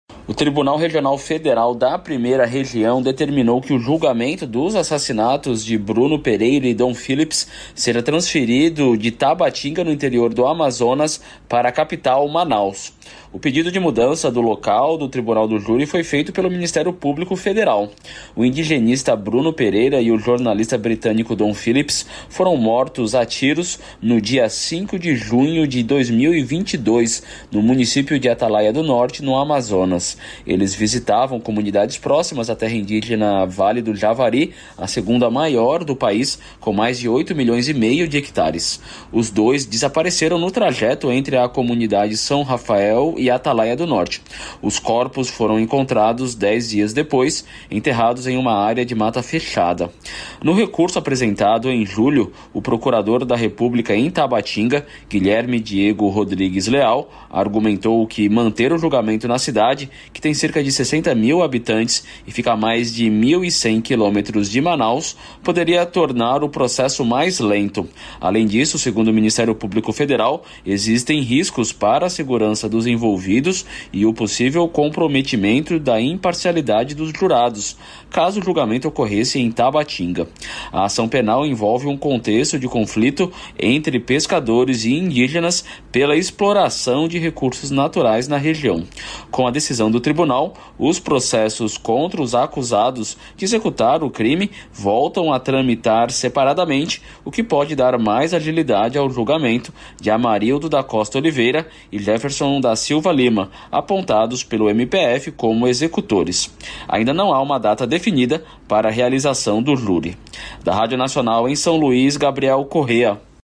Ouça na Radioagência Nacional